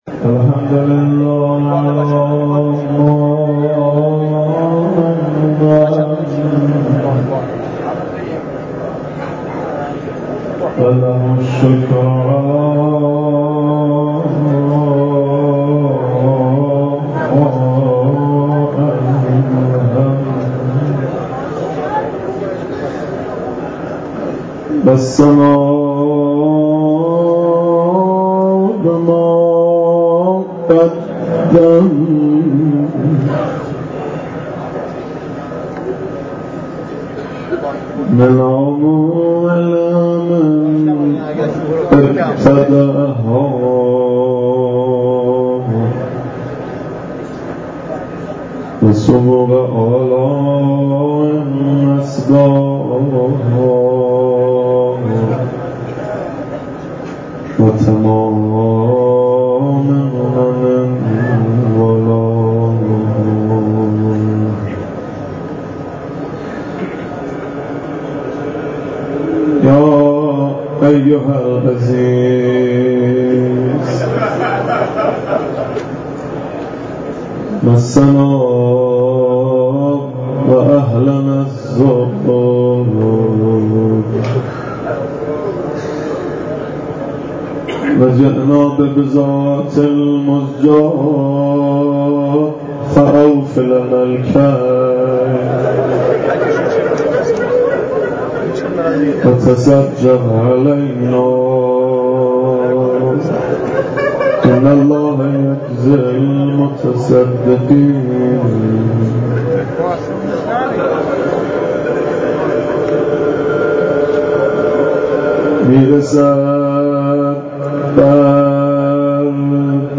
سخنرانی حجت الاسلام صدیقی مداحی حاج مهدی رسولی در مسجد حنانه